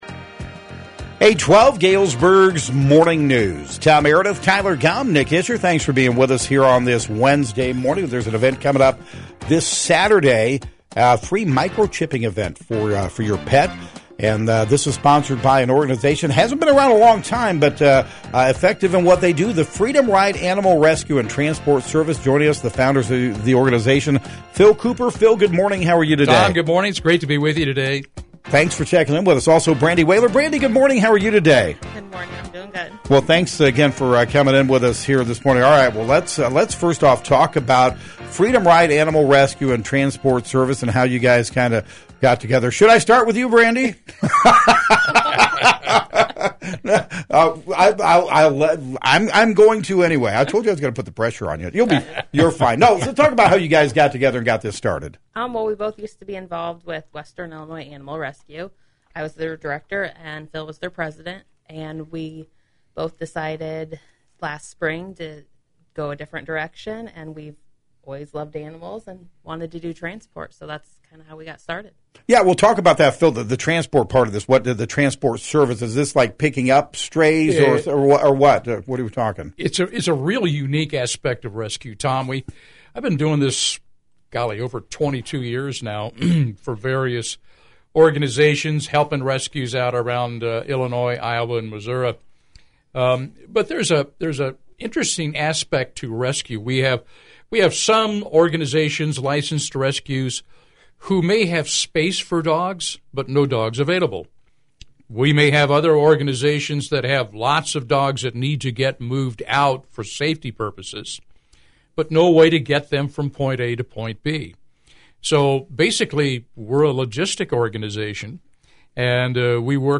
“Galesburg’s Morning News”